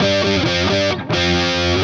AM_HeroGuitar_130-G01.wav